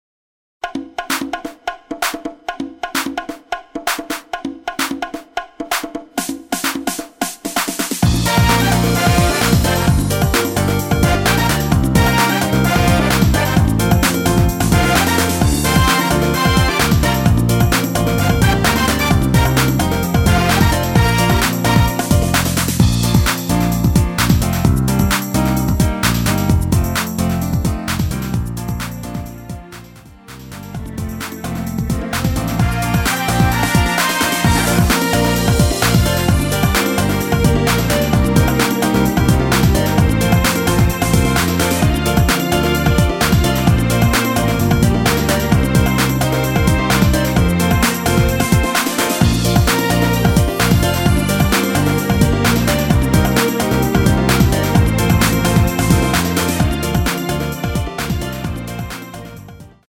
MR입니다.
원곡의 보컬 목소리를 MR에 약하게 넣어서 제작한 MR이며